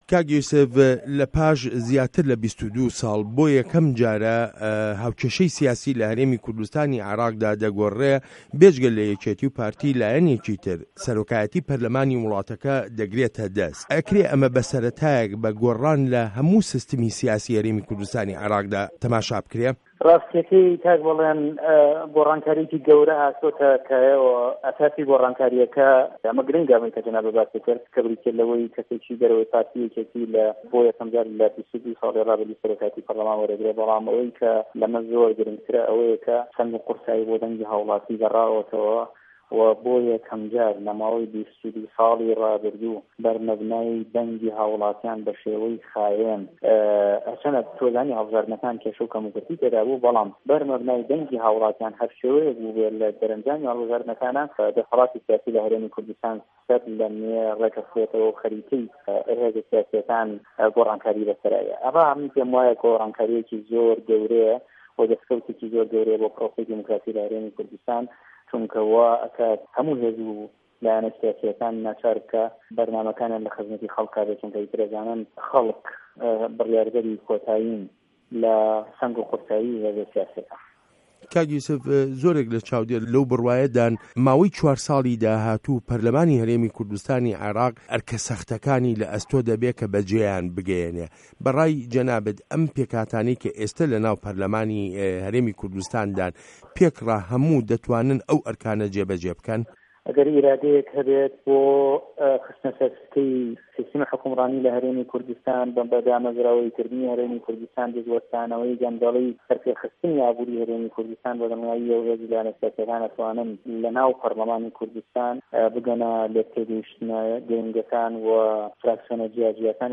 وتووێژ له‌گه‌ڵ سه‌رۆکی په‌رله‌مانی هه‌رێمی کوردستانی عێراق